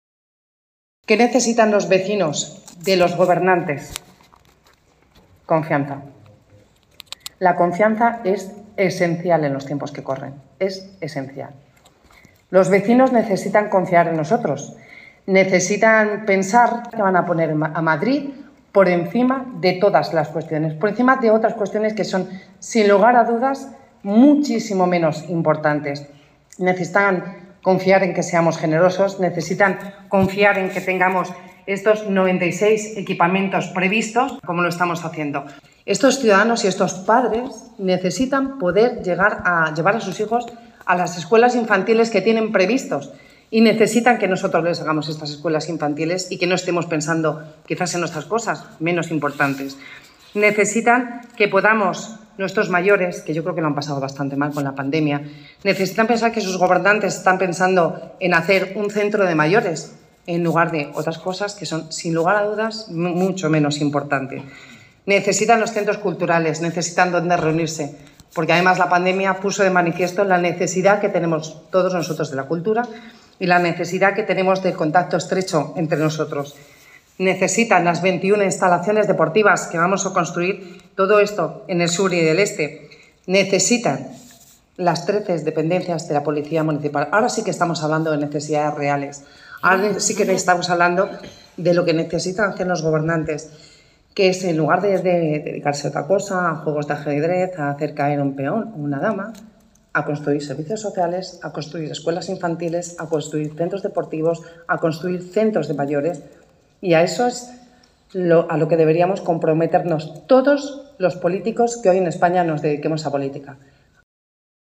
La vicealcaldesa, Begoña Villacís, señala la importancia de estos equipamientos en los distritos y la cercanía que supone para los ciudadanos: